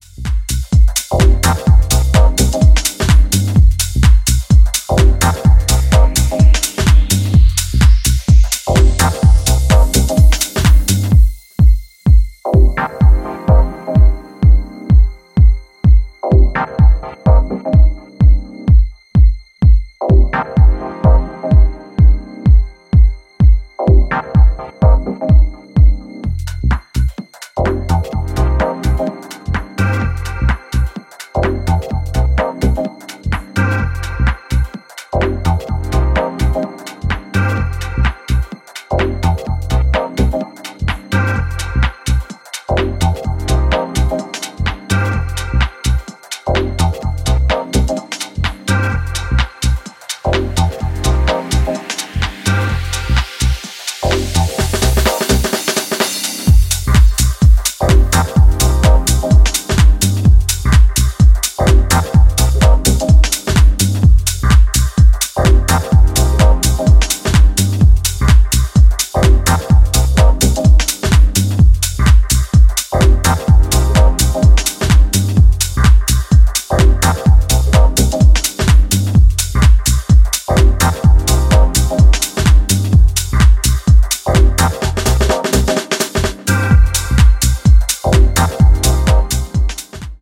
残響するシンセがフロアをスライドしていく